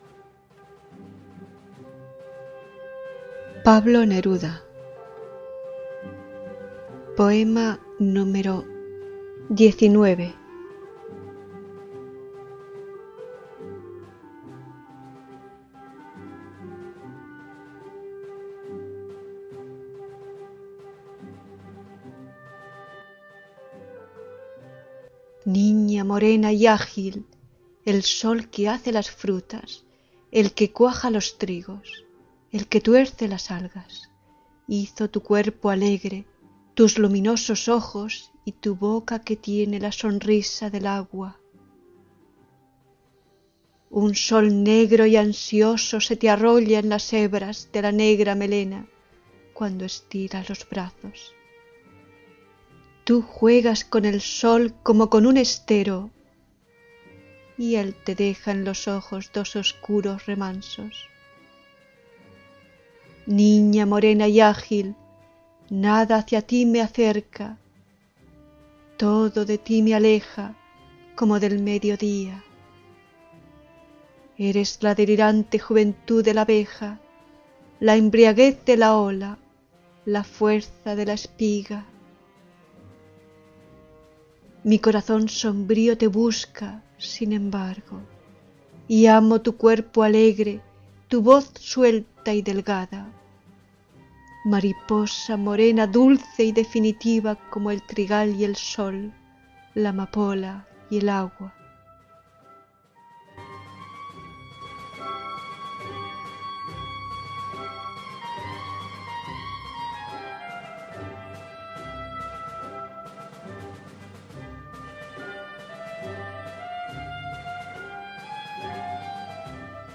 (Испания)